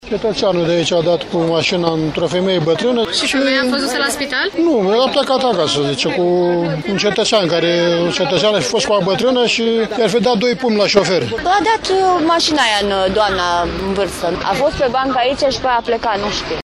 Un trecător a fost vizibil deranjat pentru că şoferul nu a fost atent în trafic şi i-a dat doi pumni şoferului, susţin martorii: